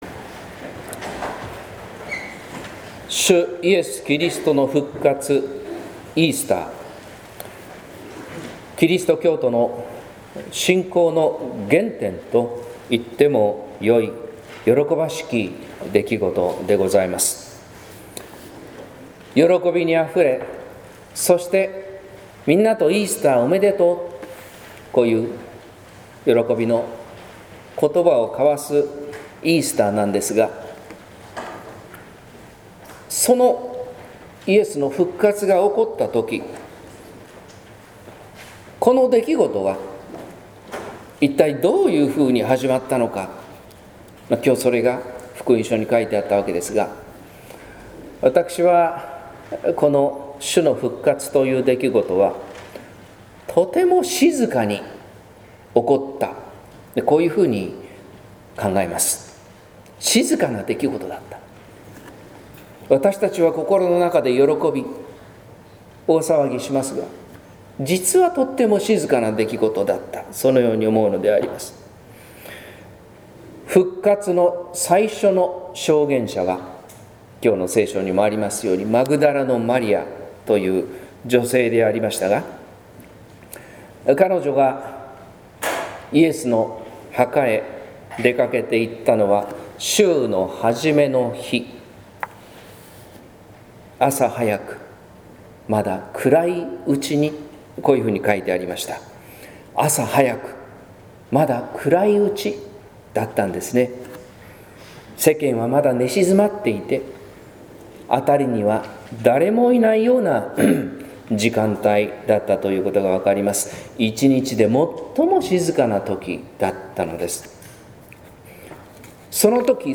説教「復活の朝、佇むマリア」（音声版） | 日本福音ルーテル市ヶ谷教会